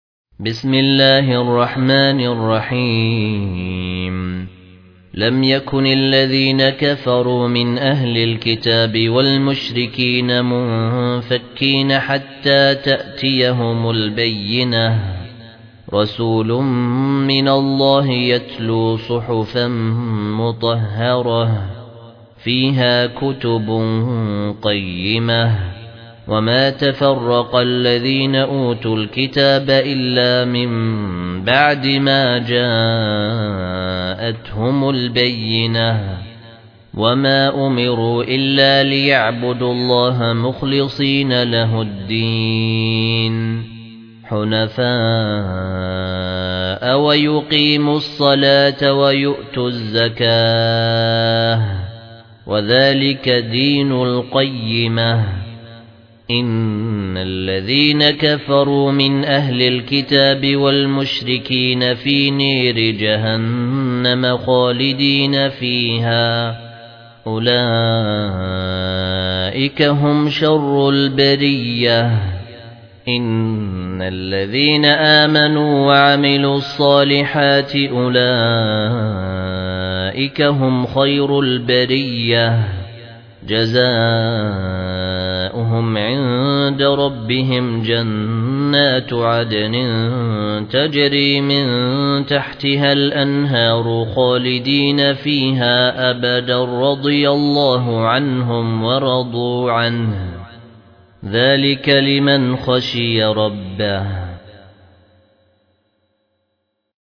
المصحف المرتل - الدوري عن أبي عمرو البصري